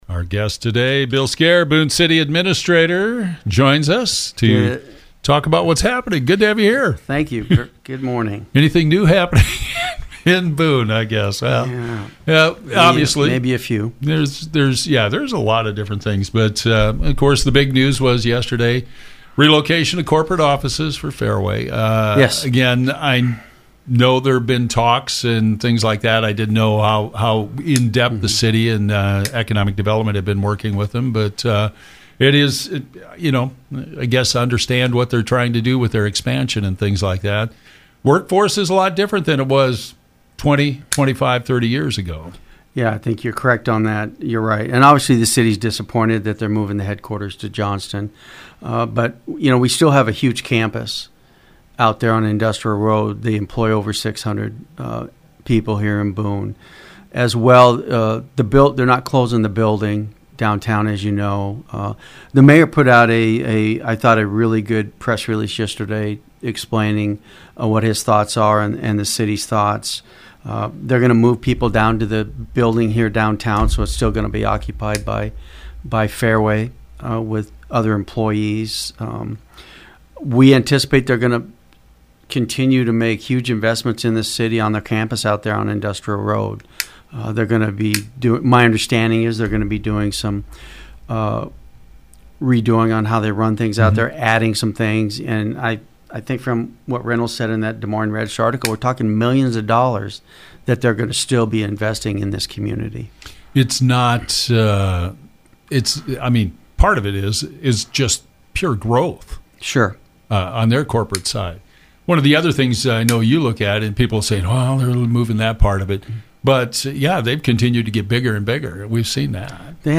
Bill Skare, Boone City Administrator talks about the recent announcement by Fareway relocating their headquarters to Johnston. He also talks about construction projects, work at both the water works and water environment plants and much more.